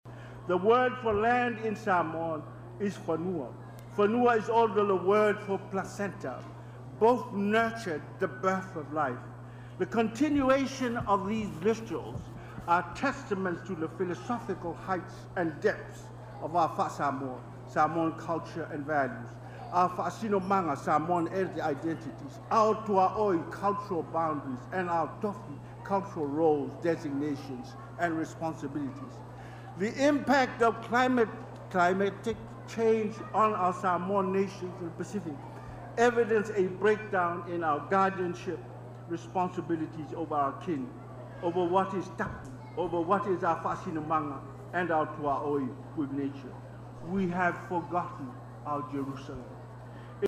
HEAD-OF-STATES-FLAGDAY-3MIN.mp3